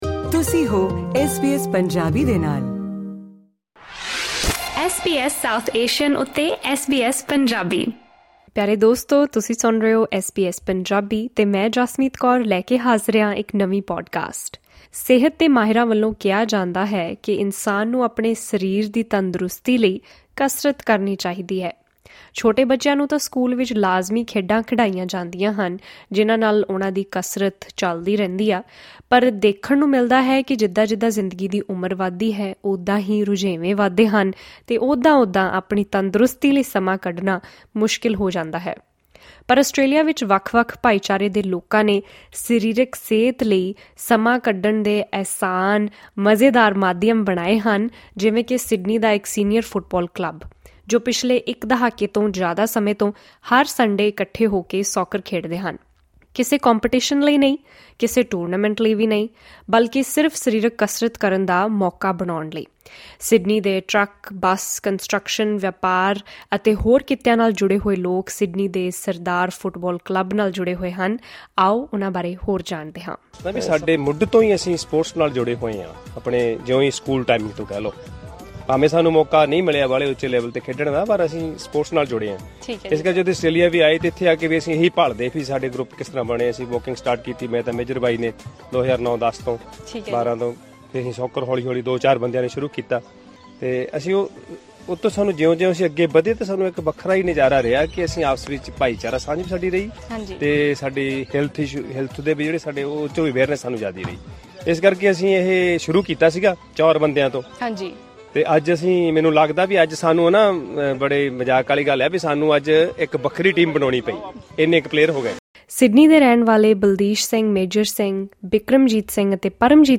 ਸਿਡਨੀ ਦੇ ‘ਸਰਦਾਰ ਫੁੱਟਬਾਲ ਕਲੱਬ’ ਦੇ ਮੈਂਬਰਾਂ ਦਾ ਕਹਿਣਾ ਹੈ ਕਿ ਜਦੋਂ ਸਰੀਰਕ ਕਸਰਤ ਨੂੰ ਇਕੱਠੇ ਮਿਲ ਕੇ ਮਜ਼ੇਦਾਰ ਗਤੀਵਿਧੀ ਬਣਾਇਆ ਜਾਵੇ, ਤਾਂ ਰੁਝੇਵਿਆਂ ਭਰੀ ਜ਼ਿੰਦਗੀ ਵਿੱਚੋਂ ਵੀ ਸਮਾਂ ਕੱਢਣਾ ਆਸਾਨ ਹੋ ਜਾਂਦਾ ਹੈ। ਚਾਰ ਲੋਕਾਂ ਨਾਲ ਸ਼ੁਰੂ ਹੋਇਆ ਇਹ ਕਲੱਬ ਅੱਜ ਸਿਰਫ਼ ਸਰੀਰਕ ਤੇ ਮਾਨਸਿਕ ਤੰਦਰੁਸਤੀ ਹੀ ਨਹੀਂ, ਬਲਕਿ ਹਰ ਐਤਵਾਰ ਦੀ ਸਾਂਝ ਅਤੇ ਮਿਲਾਪ ਦਾ ਵੀ ਸਹਾਰਾ ਬਣ ਚੁੱਕਾ ਹੈ। ਇਸ ਗੱਲਬਾਤ ਵਿੱਚ ਜਾਣੋ ਕਿ ਕਿਵੇਂ ਟਰੱਕ, ਬੱਸ ਅਤੇ ਹੋਰ ਵੱਖ-ਵੱਖ ਕਿੱਤਿਆਂ ਨਾਲ ਜੁੜੇ ਪਰਵਾਸੀ ਪੰਜਾਬੀ ਪਿਛਲੇ ਇੱਕ ਦਹਾਕੇ ਤੋਂ ਨਿਯਮਤ ਤੌਰ ’ਤੇ ਸੌਕਰ ਖੇਡ ਕੇ ਆਪਣੀ ਸਿਹਤ ਦਾ ਧਿਆਨ ਰੱਖ ਰਹੇ ਹਨ।